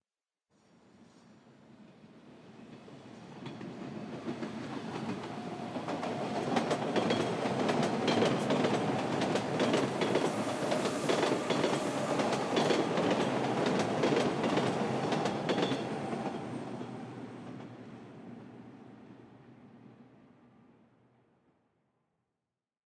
poezd.ogg